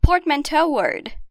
Confira a pronúncia em inglês desses dois termos:
Portmanteau-word.mp3